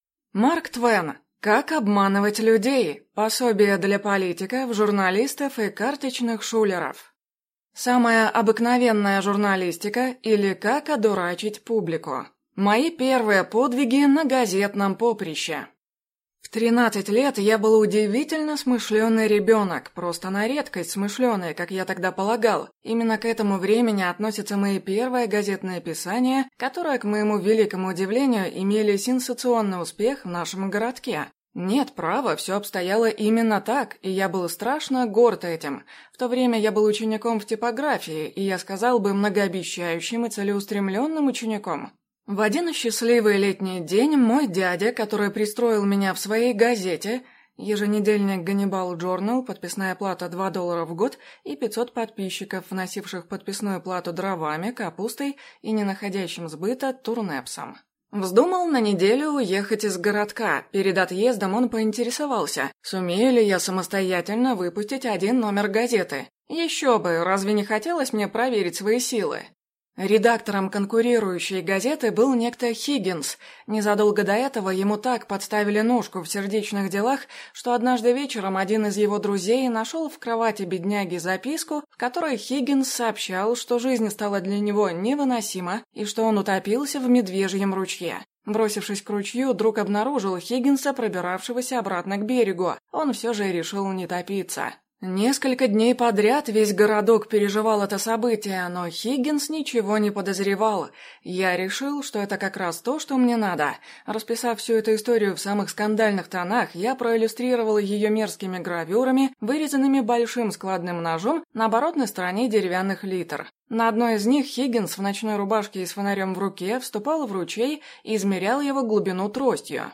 Аудиокнига Как обманывать людей. Пособие для политиков, журналистов и карточных шулеров | Библиотека аудиокниг